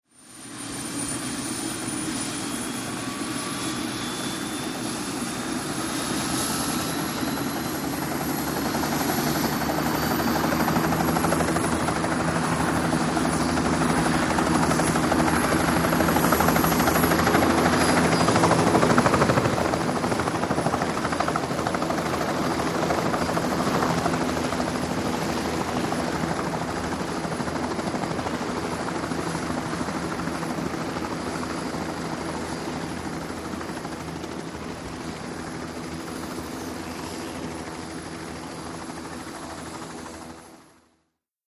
HELICOPTER JET: EXT: Warm up, take off, away.